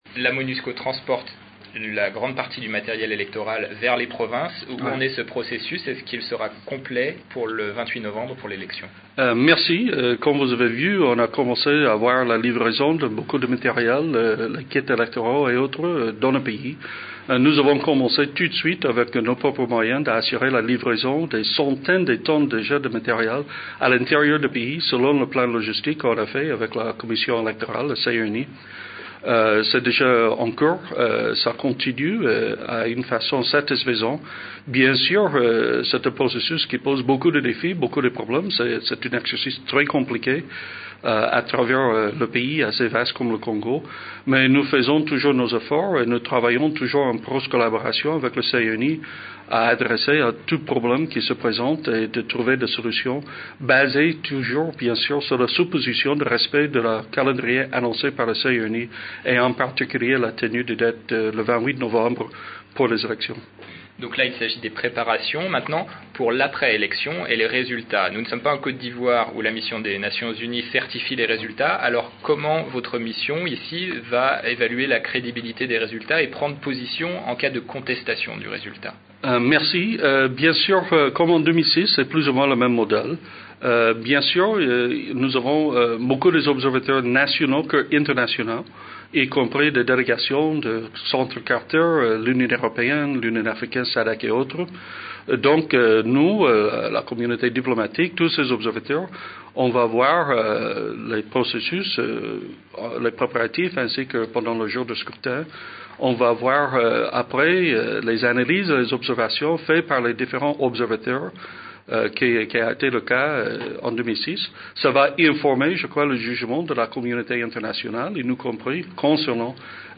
A la fin de cette rencontre, Roger Meece a accordée une interview à RFI, AFP, BBC et Radio Okapi.